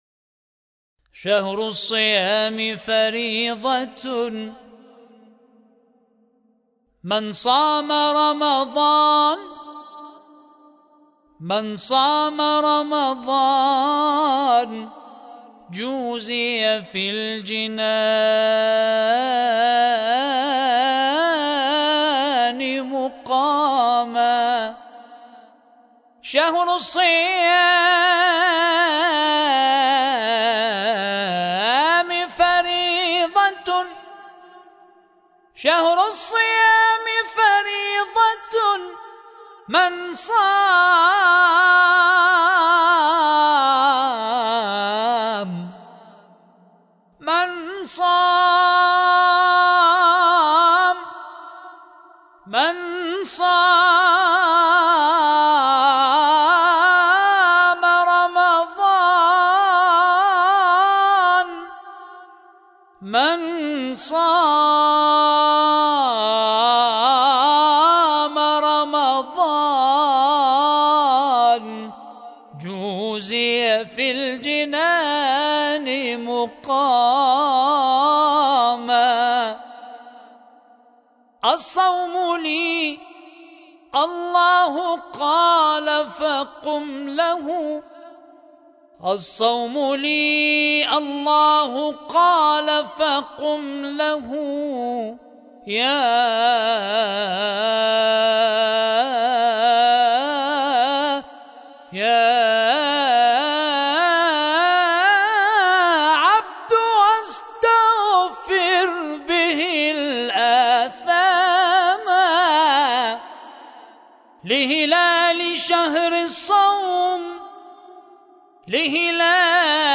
شهر الصيام فريضة ـ ابتهالات